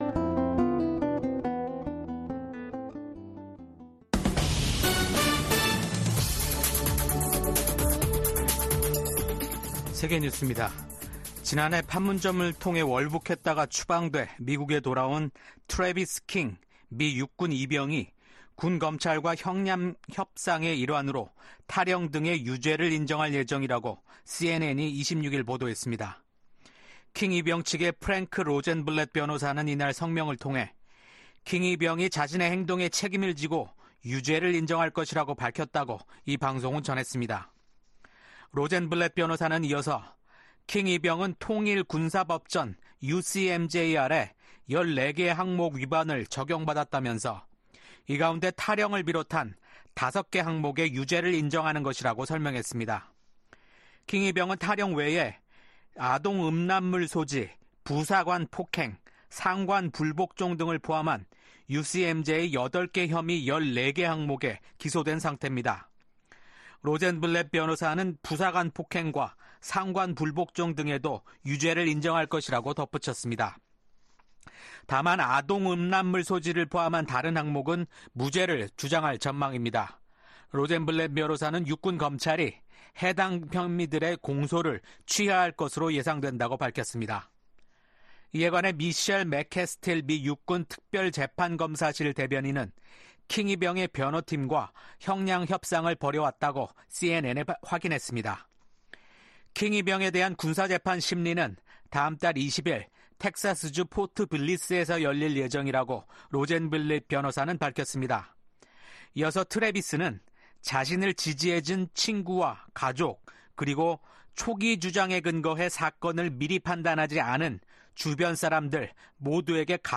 VOA 한국어 아침 뉴스 프로그램 '워싱턴 뉴스 광장' 2024년 8월 28일 방송입니다. 북한이 영변 핵 시설을 가동하고 평양 인근 핵 시설인 강선 단지를 확장하고 있다고 국제원자력기구(IAEA)가 밝혔습니다. 미국 정부가 한국에 대한 아파치 헬기 판매가 안보 불안정을 증대시킬 것이라는 북한의 주장을 일축했습니다. 북한이 새 ‘자폭용 무인기’를 공개한 가운데 미국의 전문가들은 미사일보다 저렴한 비용으로 한국의 방공망을 위협할 수 있다고 우려했습니다.